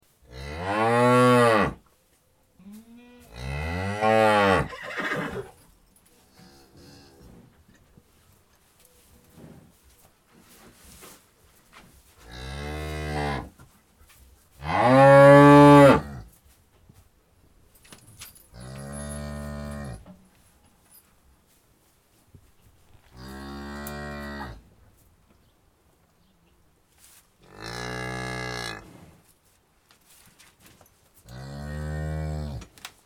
دانلود صدای گاو نر محلی از ساعد نیوز با لینک مستقیم و کیفیت بالا
جلوه های صوتی